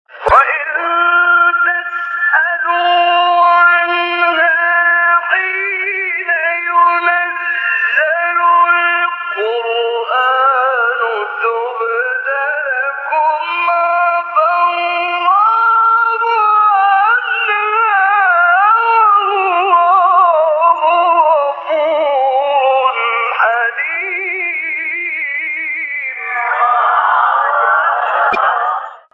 آیه 101 سوره مائده استاد محمد عمران | نغمات قرآن | دانلود تلاوت قرآن